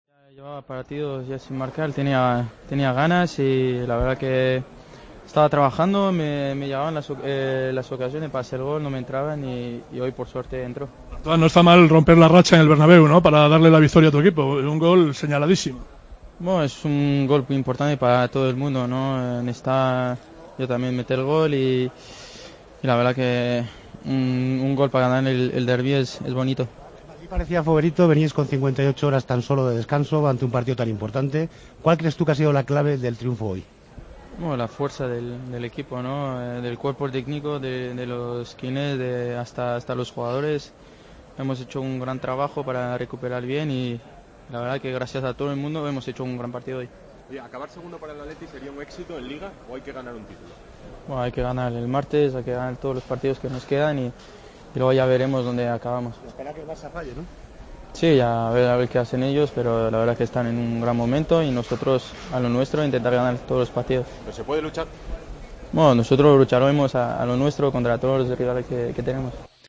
El francés, en zona mixta, valoró el trabajo del cuerpo técnico rojiblanco en la victoria ante el Real Madrid, tras el poco tiempo que tuvo el Atlético de Madrid para preparar el derbi del Santiago Bernabéu.